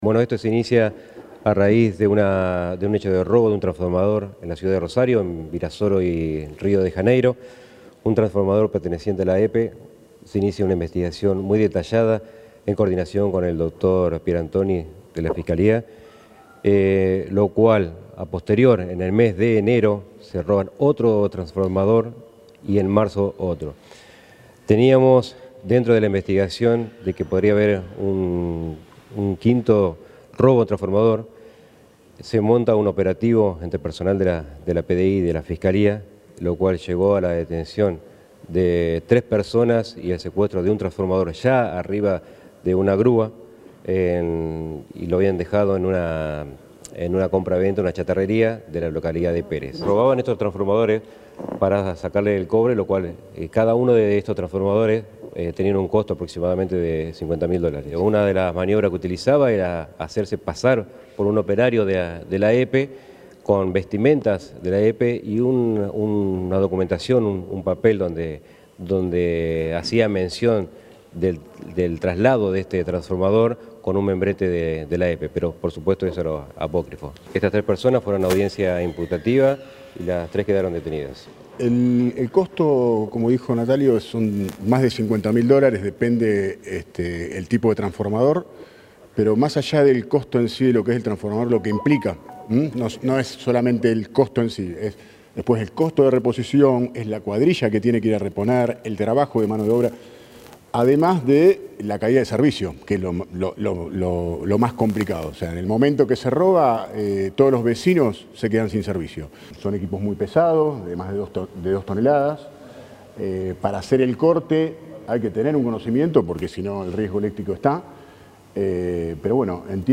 El jefe de Policía de Investigaciones, Natalio Marciani, y el vicepresidente de la Empresa Provincial de la Energía, Lisandro Peresutti, brindaron detalles de las actuaciones que llevaron a tres hombres a prisión preventiva tras la imputación de Fiscalía.
Declaraciones Marciani y Peresutti